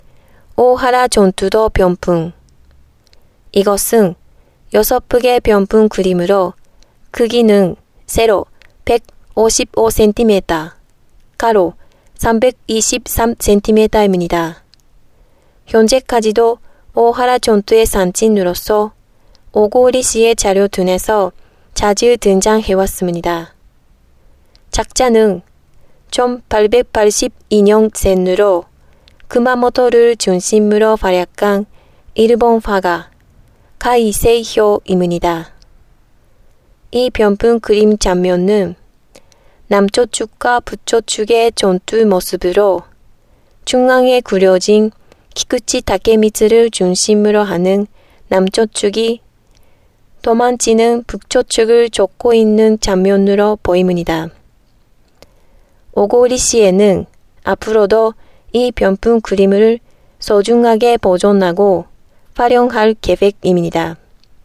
小郡市内文化財音声ガイダンス